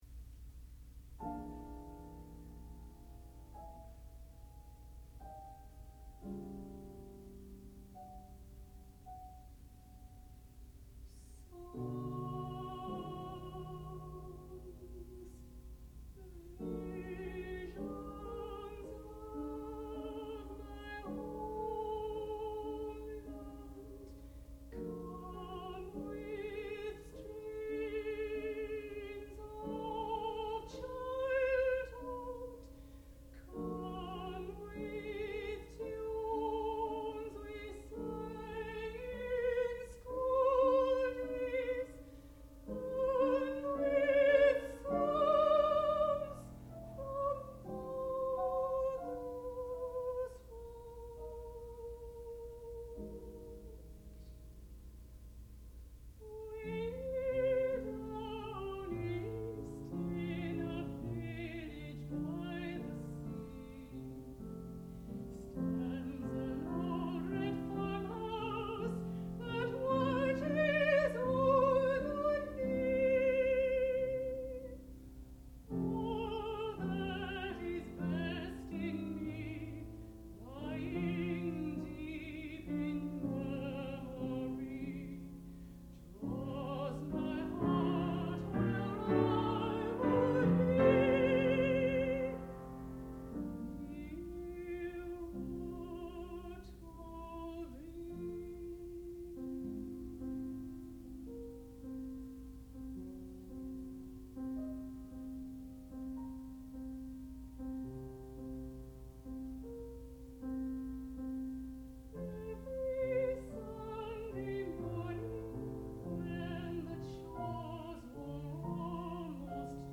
sound recording-musical
classical music
mezzo-soprano
piano